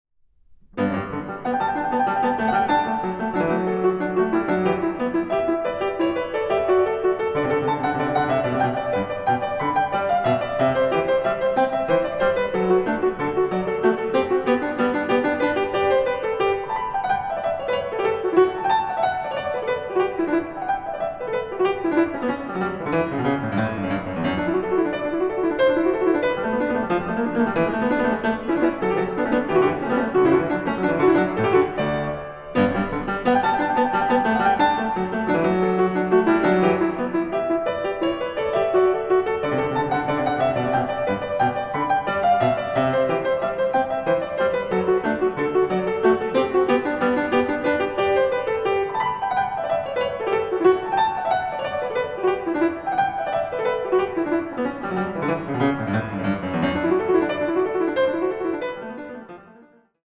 Pianistin